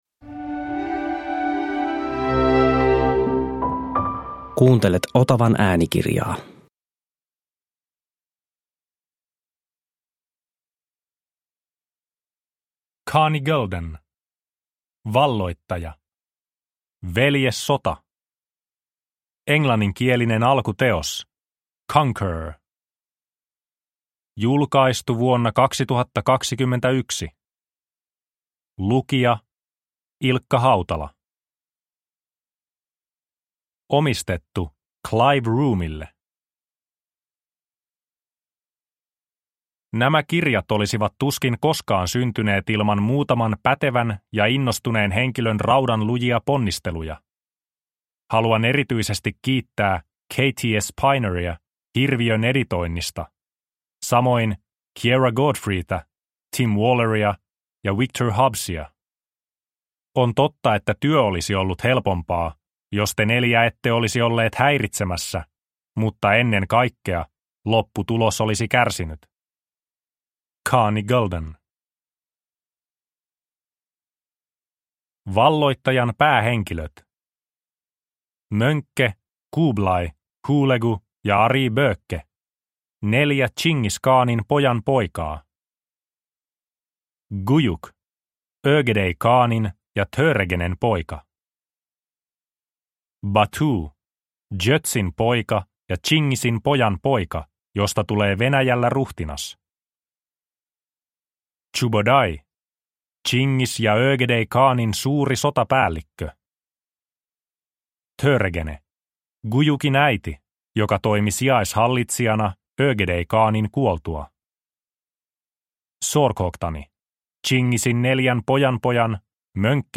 Veljessota – Ljudbok – Laddas ner